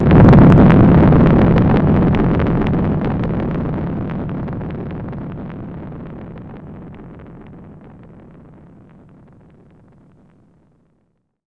explo4.wav